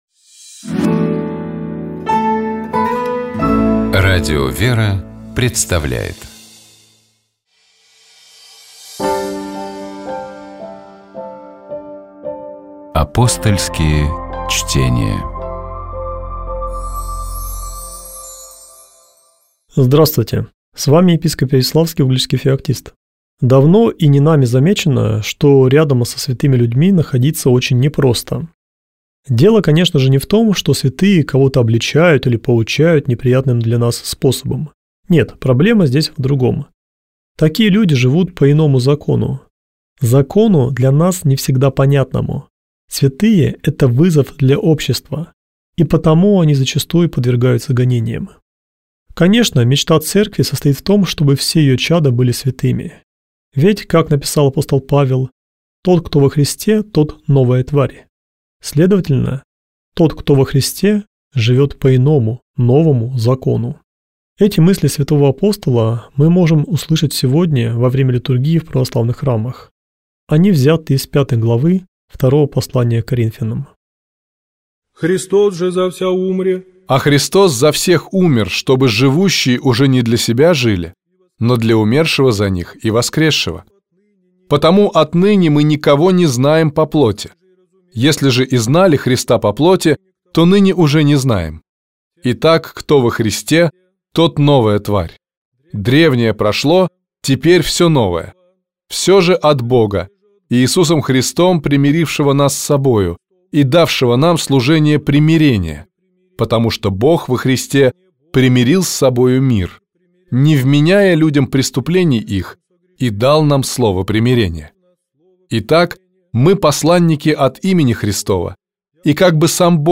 Ведущие программы: Тутта Ларсен